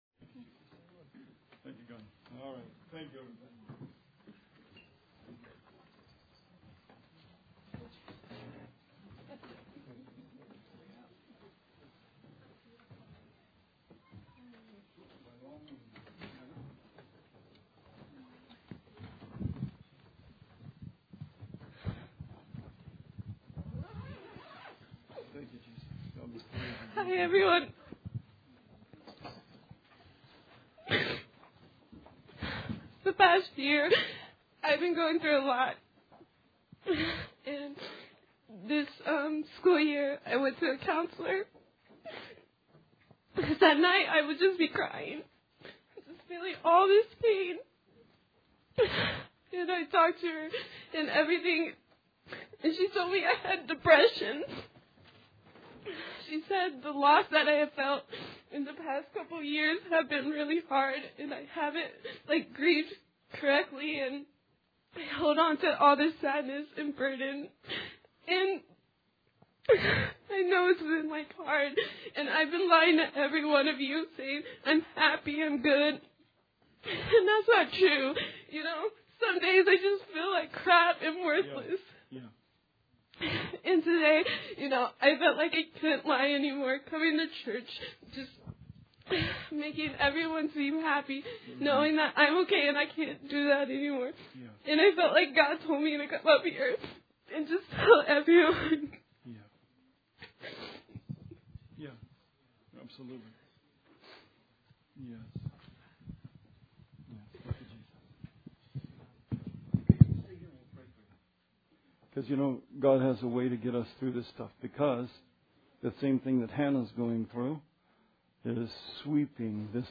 Sermon 1/10/16